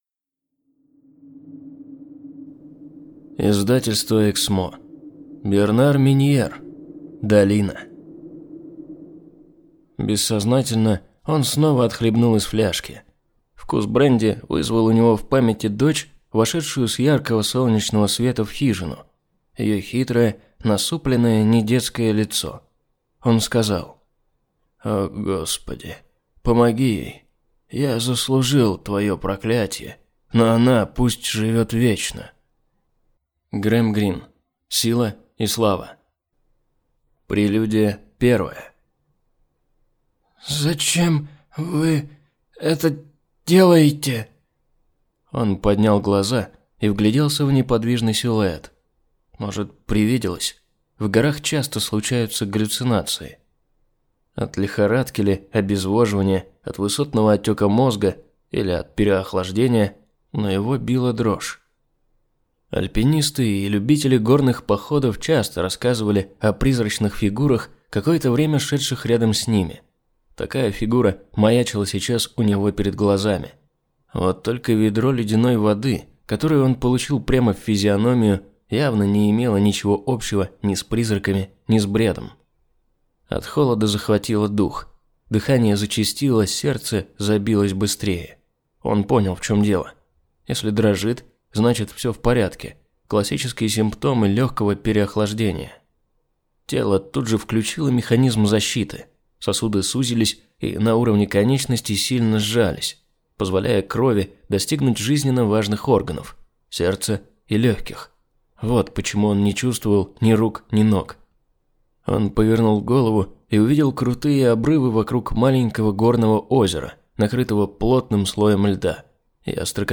Аудиокнига Долина | Библиотека аудиокниг